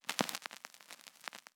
Aguja de un tocadiscos sobre vinilo
Sonidos: Hogar